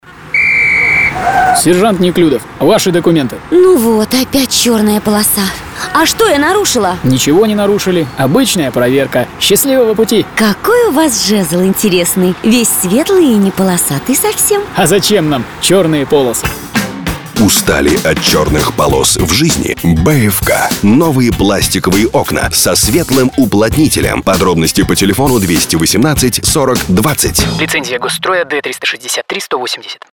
Вид рекламы: Радиореклама